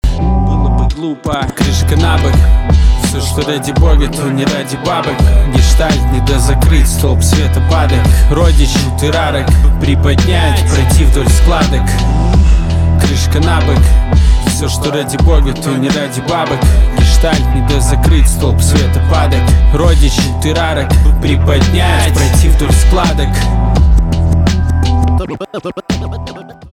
русский рэп
битовые , басы , качающие